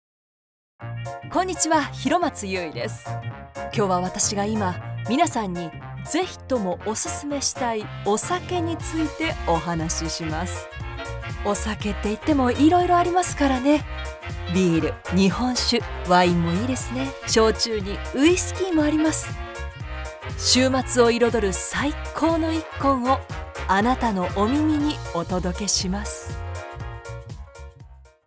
Con mi estudio en casa equipado con equipos de primer nivel, estoy lista para darle vida a tu proyecto.
Una voz nítida que cautiva a tu audiencia
Conversacional, versátil, elegante, lujosa, auténtica, autoritaria, conversacional, profunda, confiable, optimista, amigable y creíble.
Micrófono: NEUMANN TLM102